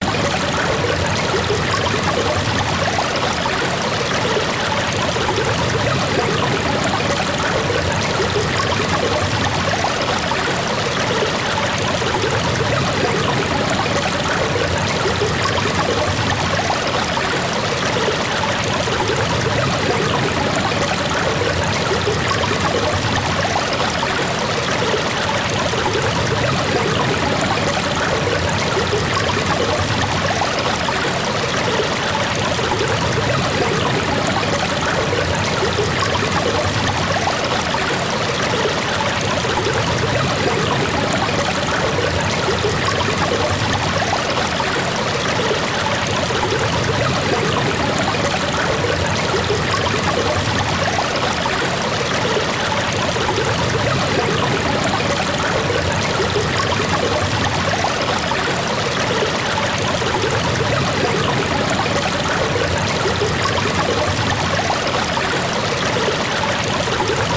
Sleep Water Sound Button - Free Download & Play